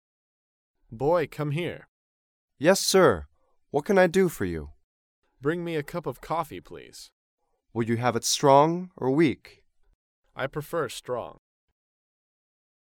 dialogue
英语情景对话